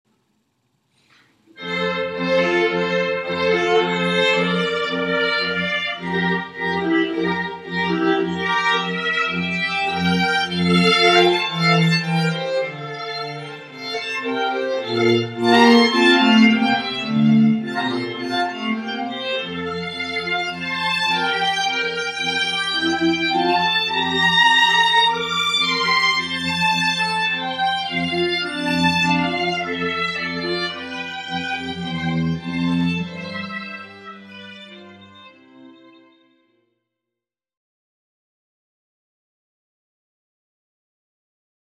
Click on the songs below to listen to our string trio.